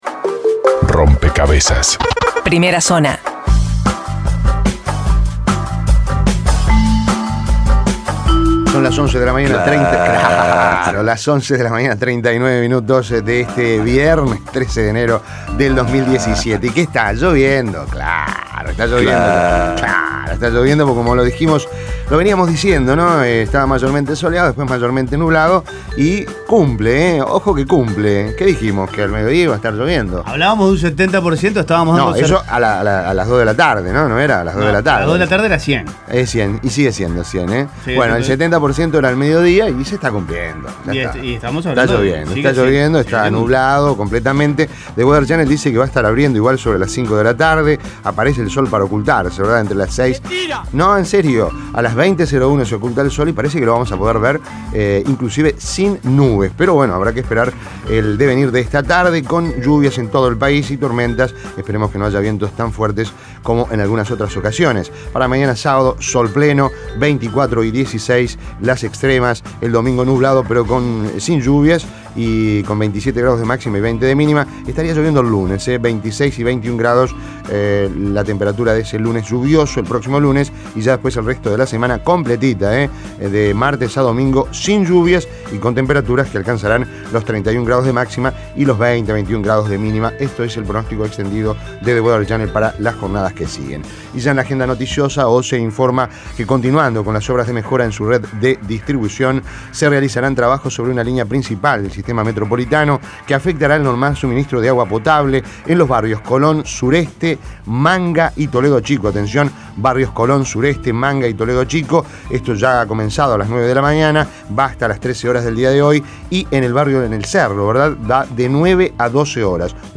Las principales noticias del día, resumidas en la Primera Zona de Rompkbzas.